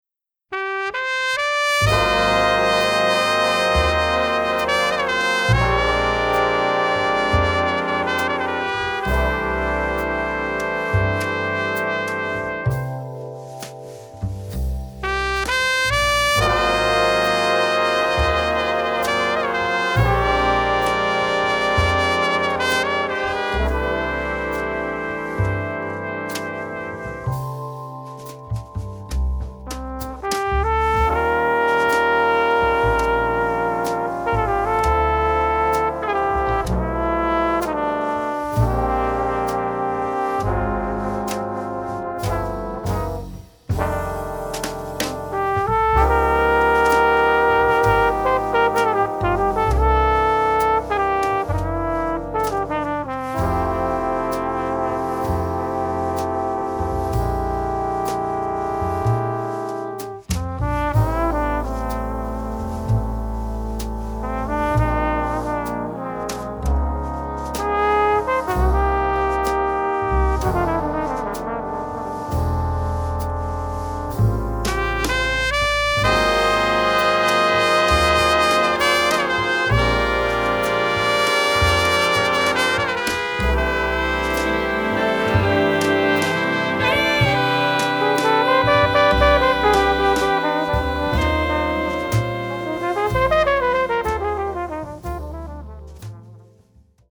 Bass, Keyboard, Composer, Arranger, Orchestrator, Conductor
Drums
Congas
Lead Alto Saxophone, Flute, Clarinet, Piccolo
Tenor & Soprano Saxophones
Baritone Saxophone, Bass Clarinet
Lead Trumpet, Flugelhorn
Bass Trombone
Piano